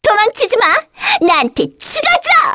Pansirone-Vox_Skill1_kr.wav